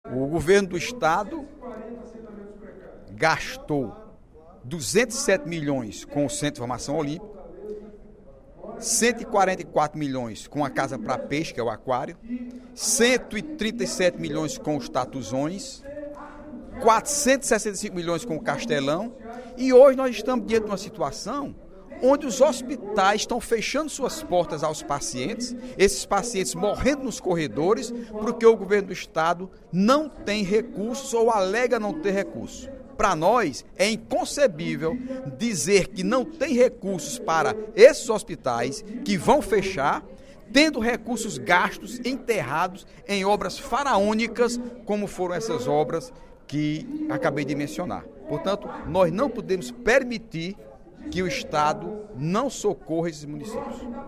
O deputado Heitor Férrer (PSB) criticou, durante o primeiro expediente da sessão plenária desta quarta-feira (22/02), recursos públicos direcionados para obras que considera supérfluas pela gestão anterior do Governo Estadual, enquanto agora falta dinheiro para o setor de saúde.